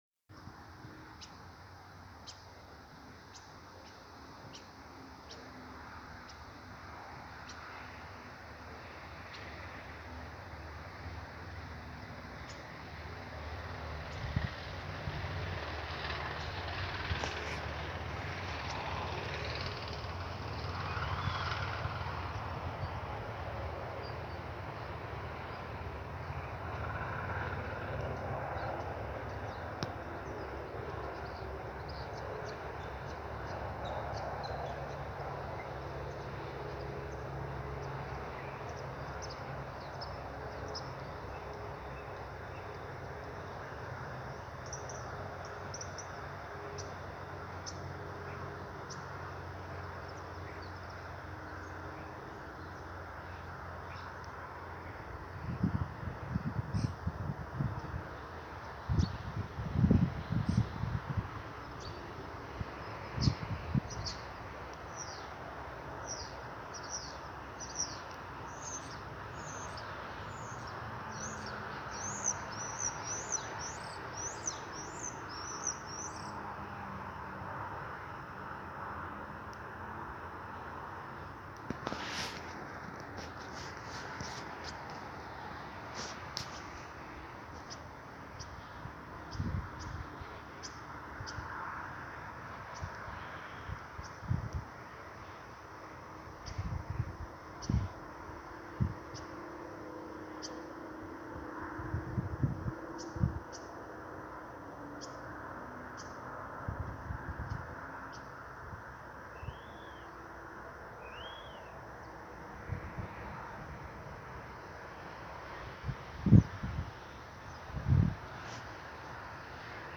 скворец, Sturnus vulgaris
СтатусСлышен голос, крики
Примечания/kaut kur augstu eglē skandalēja